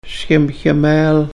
Listen to the elders